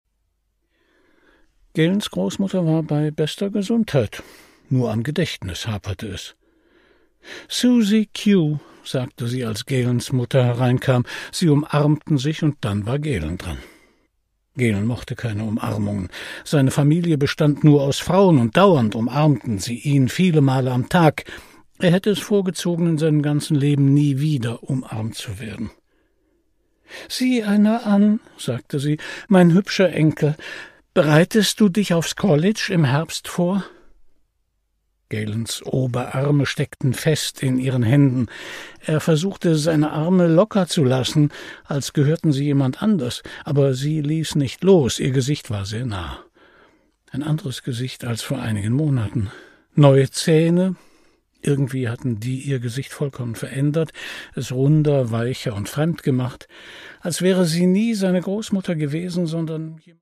Produkttyp: Hörbuch-Download
Gelesen von: Christian Brückner